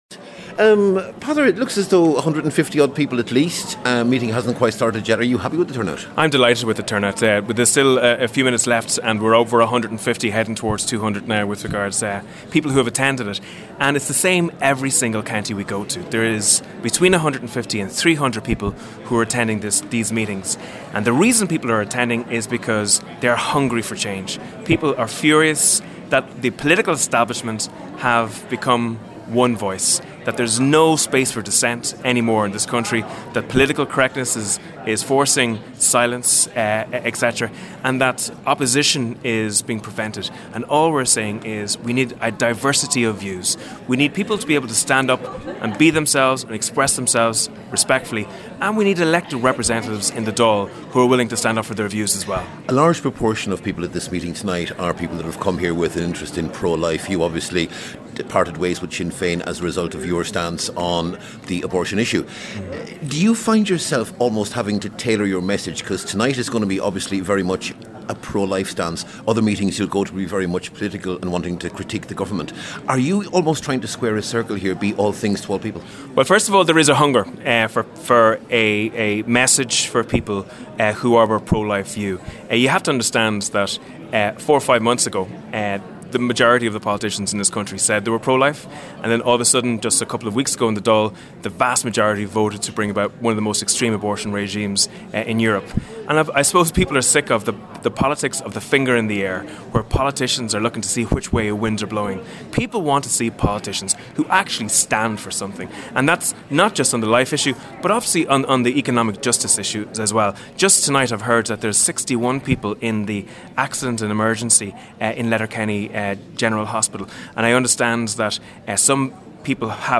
Around 200 people attended a meeting in Letterkenny last night hosted by Deputy Peadar Toibin, who is in the process of founding a new political party.